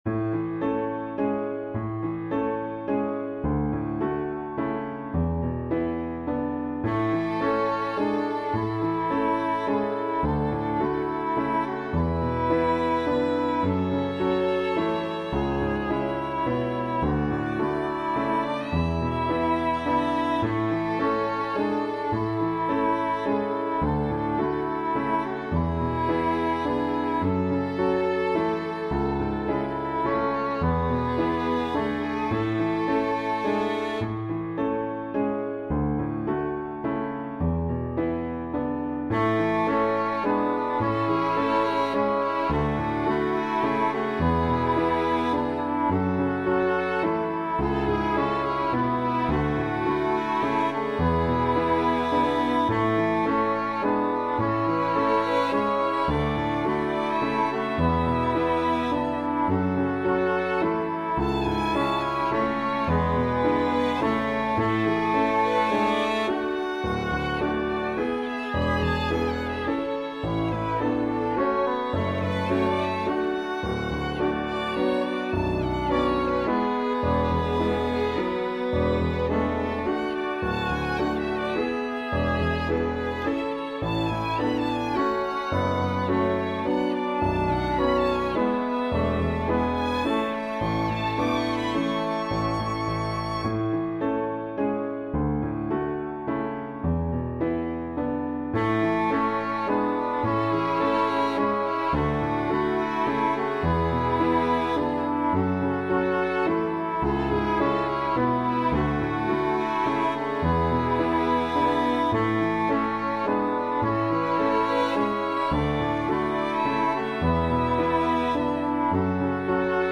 Medium Voice/Low Voice
Violin Optional Obbligato/Violin Accompaniment
Love the violin.